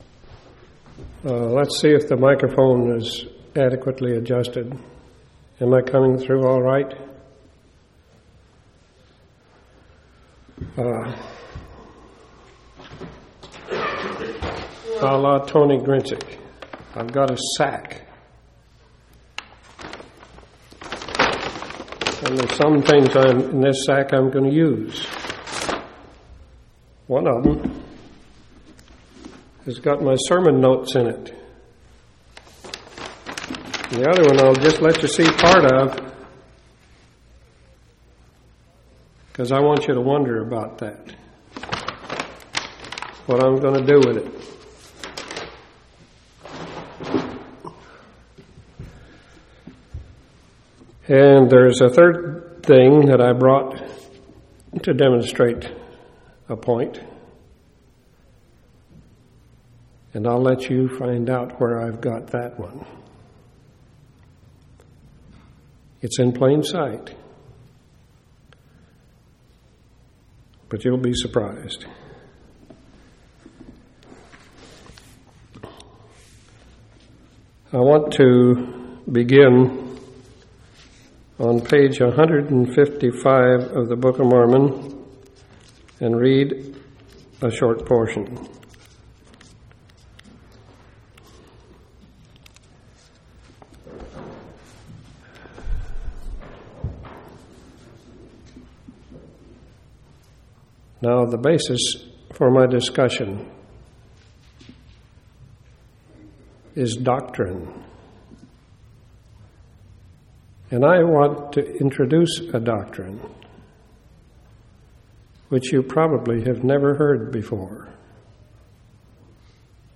11/29/1992 Location: Temple Lot Local Event